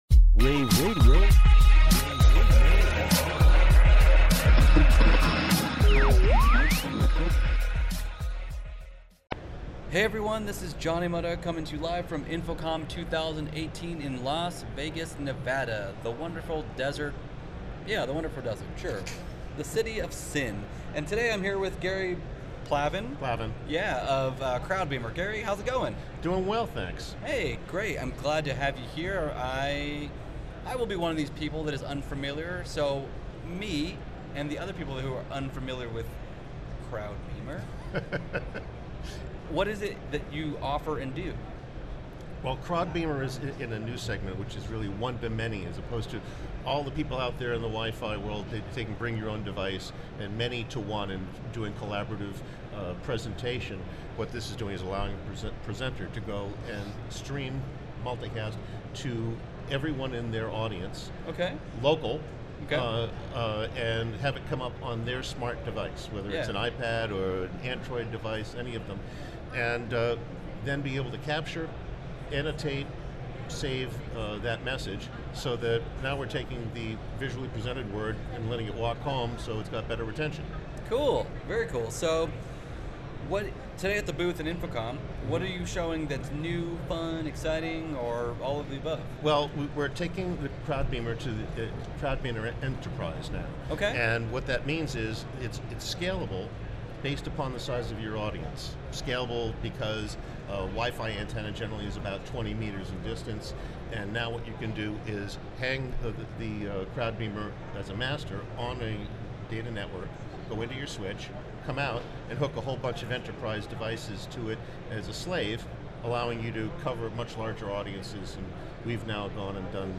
June 9, 2018 - InfoComm, InfoComm Radio, Radio, rAVe [PUBS], The Trade Show Minute,